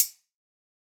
Closed Hats
BWB VAULT HATS (Childs Play).wav